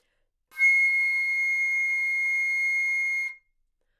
长笛单音 " 单音的整体质量 长笛 C7
描述：在巴塞罗那Universitat Pompeu Fabra音乐技术集团的goodsounds.org项目的背景下录制。
Tag: C6 纽曼-U87 单注 多重采样 好声音 长笛